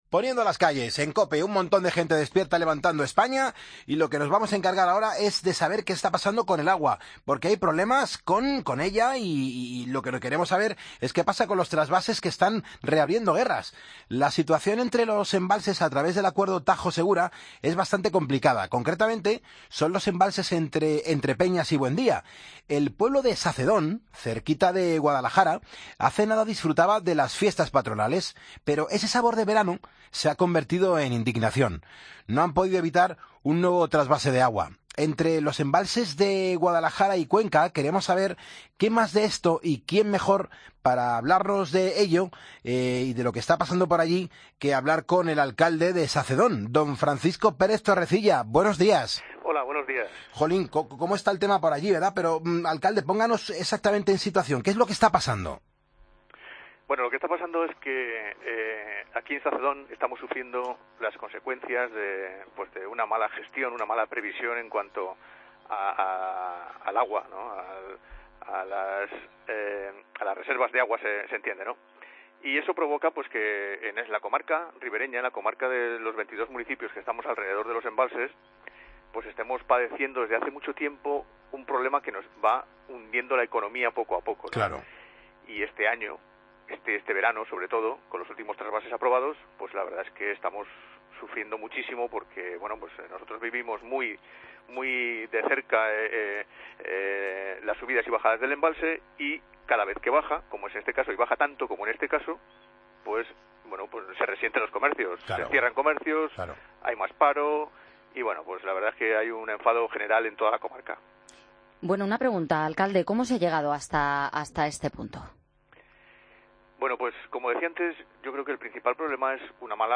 Hablamos con el alcalde de Sacedón, Francisco Pérez Torrecilla